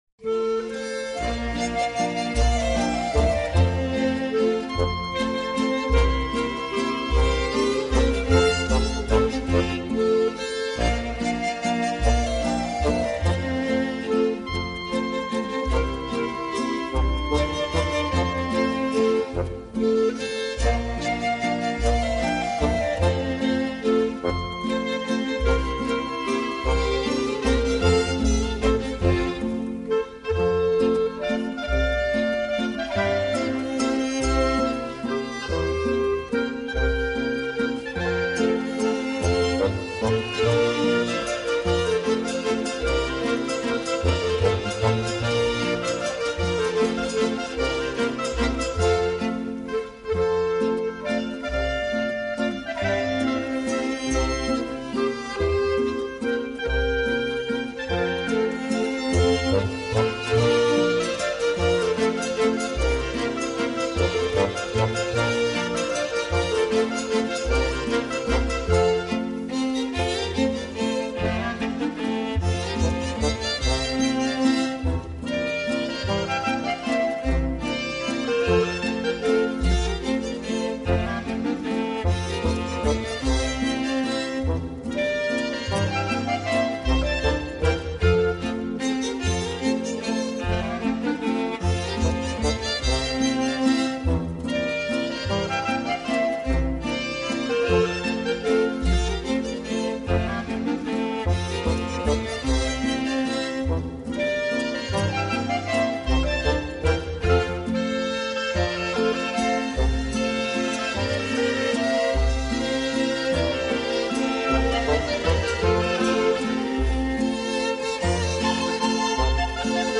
Boehmischer Landler 1.MP3